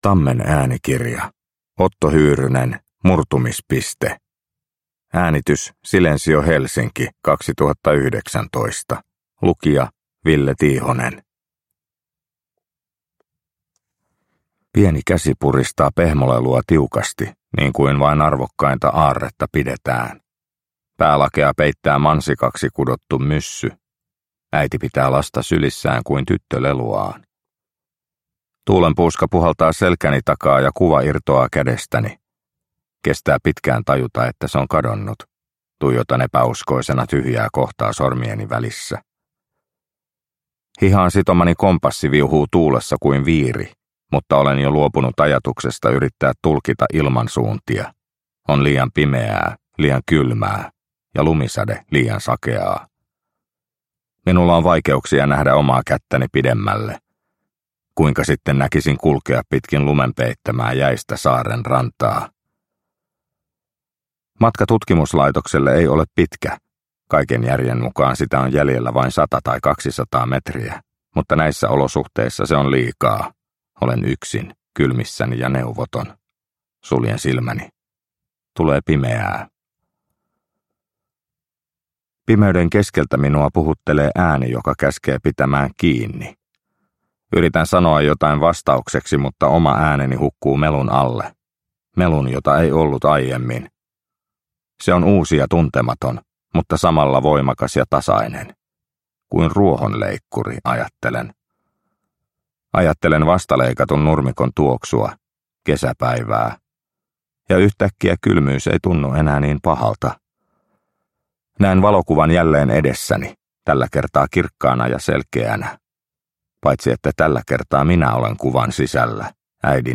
Murtumispiste – Ljudbok – Laddas ner